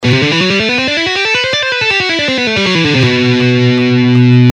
Legato in B Phrygian Scale:
Original Speed:
1.-Legato-Exercise-In-B-Phyrigian-Scale.mp3